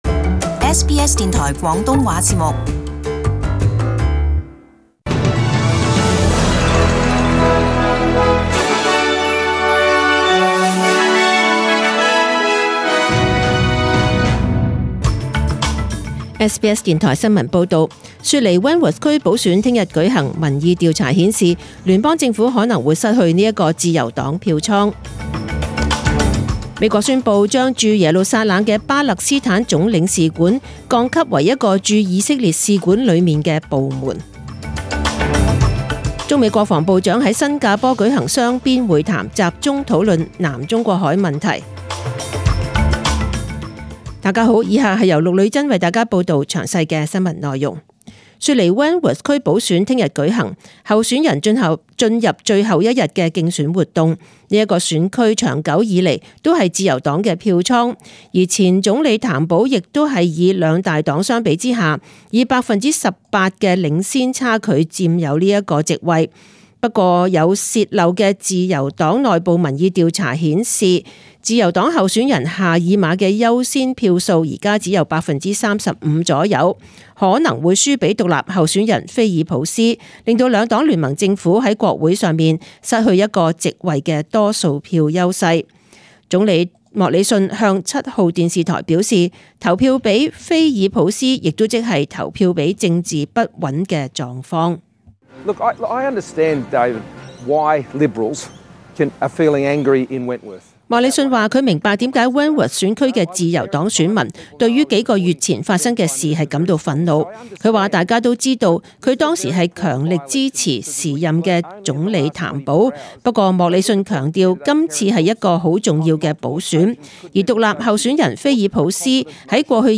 SBS中文新闻 （十月十九日）
请收听本台为大家准备的详尽早晨新闻。